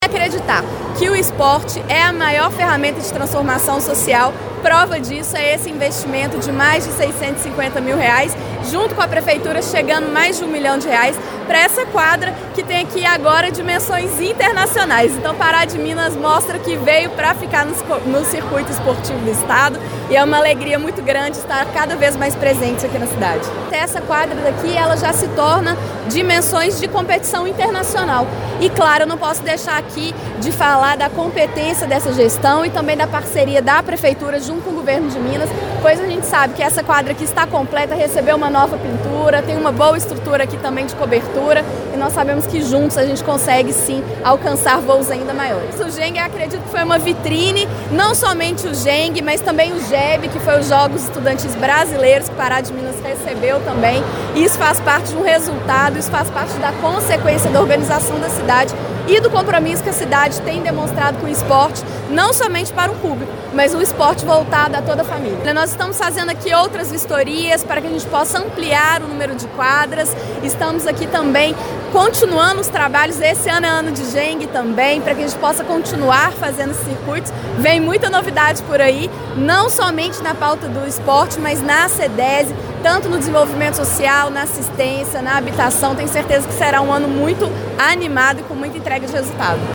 Durante a cerimônia, a secretária de Estado de Desenvolvimento Social Alê Portela destacou que o município já se tornou uma referência estadual devido à competência da gestão e à parceria contínua com o estado.